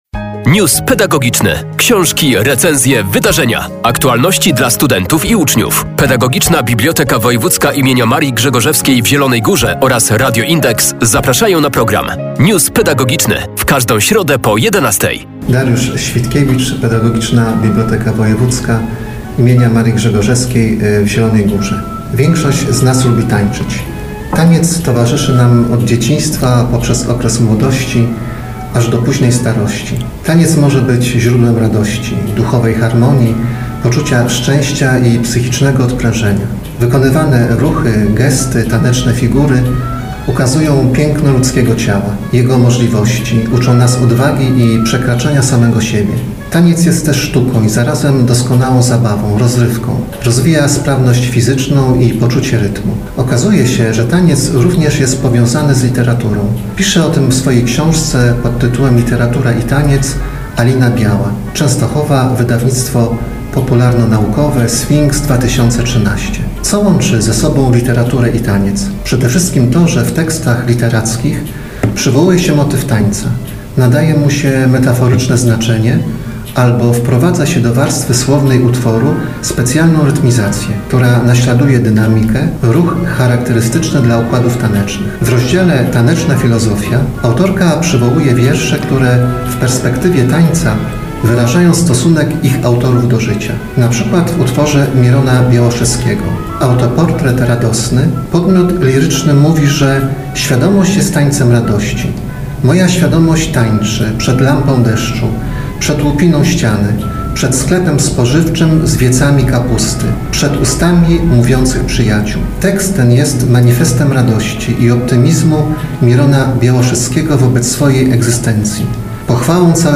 Pedagogiczna Biblioteka Wojewódzka im. Marii Grzegorzewskiej w Zielonej Górze zachęca do lektury książki. News pedagogiczny.